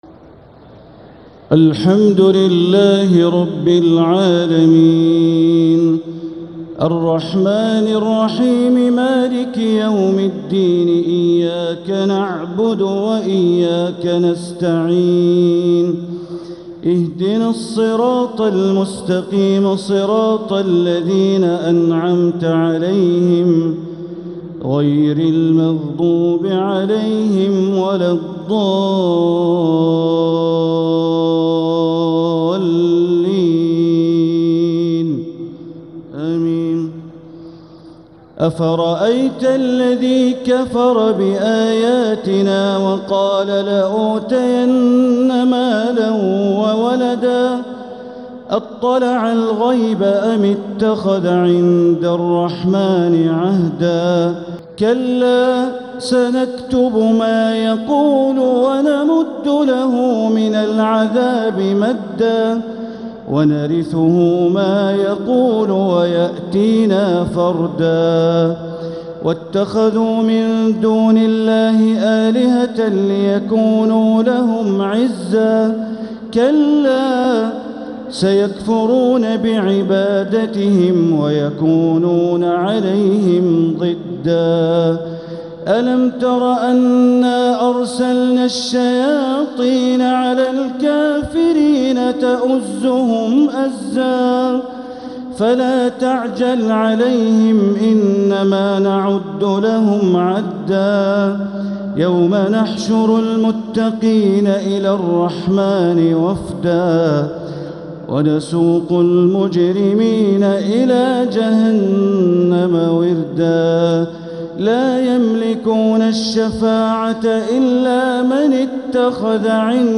Taraweeh 21st night Ramadan 1446H Surah Maryam and TaHa > Taraweeh Ramadan 1446H > Taraweeh - Bandar Baleela Recitations